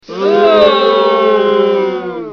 SFX起哄音效下载
SFX音效